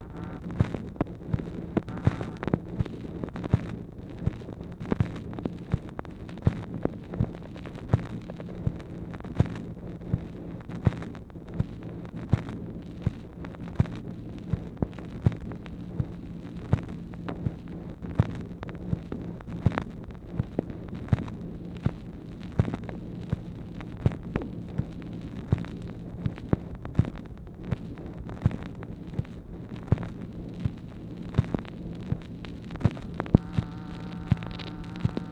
MACHINE NOISE, May 25, 1964
Secret White House Tapes | Lyndon B. Johnson Presidency